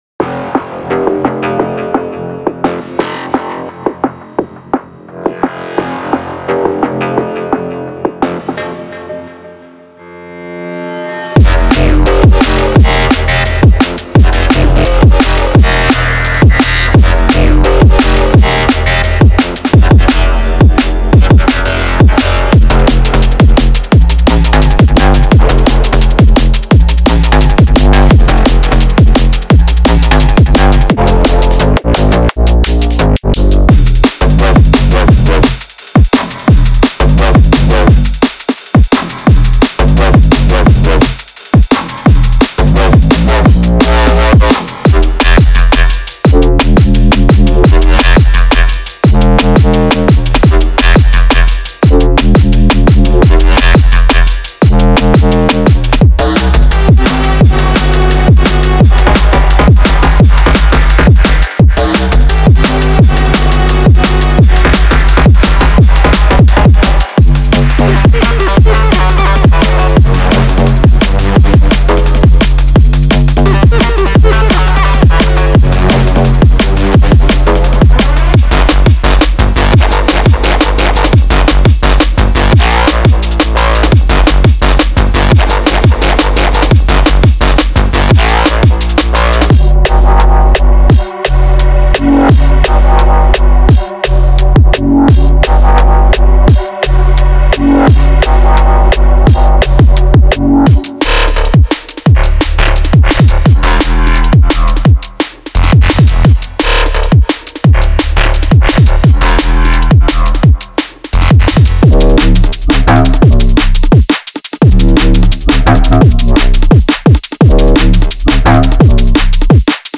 Genre:Drum and Bass
デモサウンドはコチラ↓
172 BPM